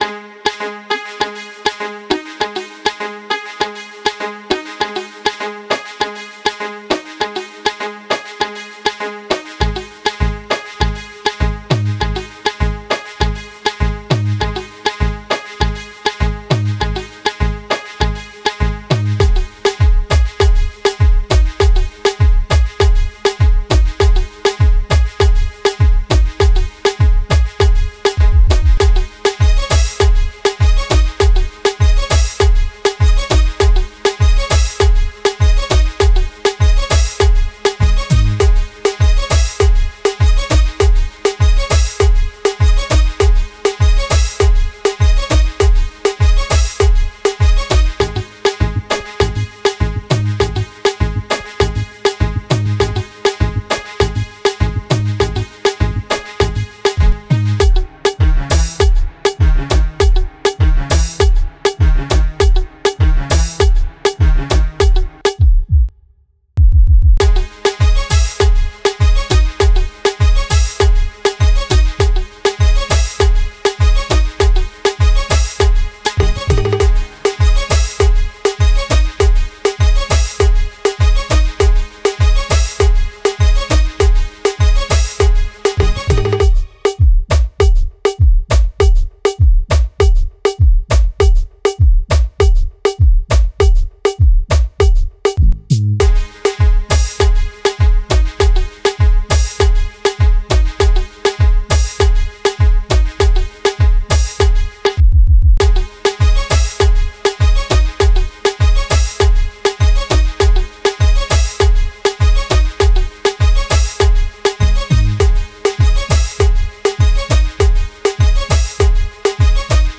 • Style: Dancehall